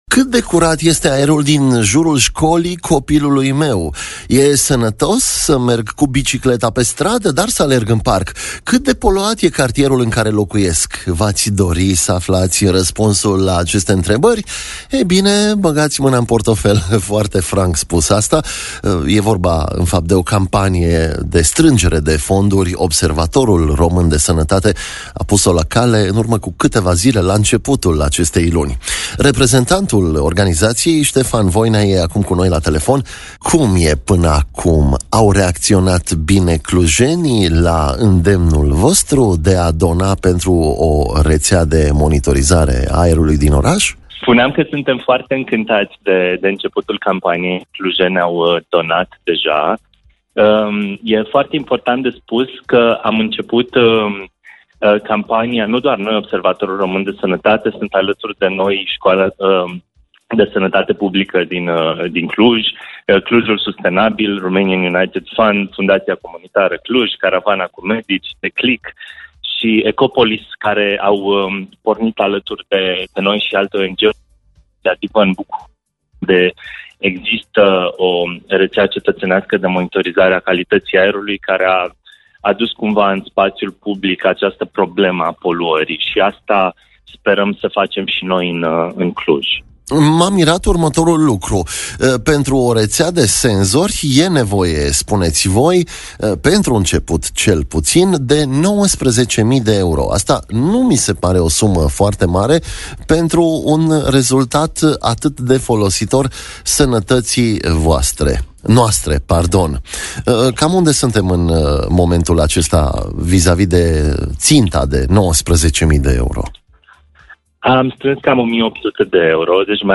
Mai multe despre acestă campanie aflați din intervul